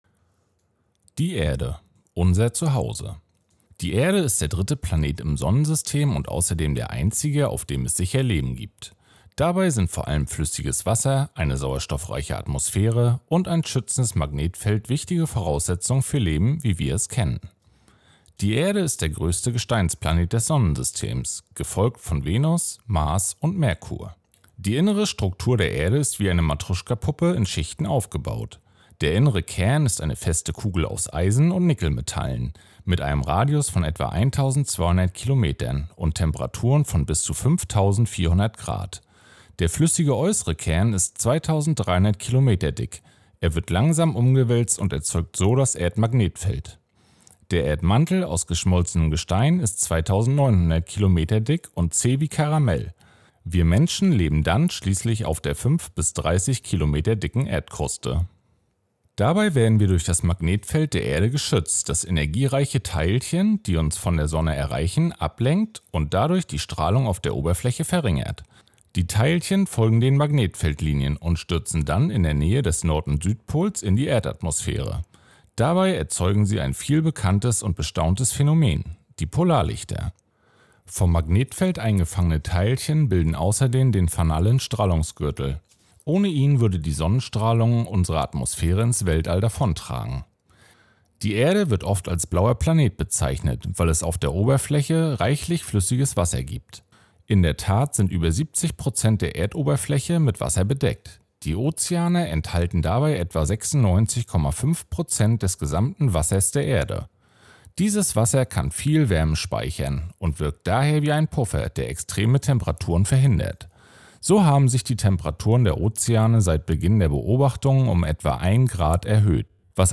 Audioguide
Promovierende vom Institut für Physik der Uni Rostock geben spannende Einblicke in ihre Forschung und erklären das Weltall.